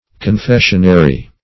Search Result for " confessionary" : The Collaborative International Dictionary of English v.0.48: Confessionary \Con*fes"sion*a*ry\, n. [LL. confessionarium.]